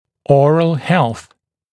[‘ɔːrəl helθ][‘о:рэл хэлс]здоровье зубочелюстной системы